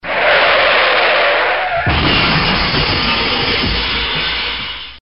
SFX汽车从刹车到碰撞的声音音效下载
SFX音效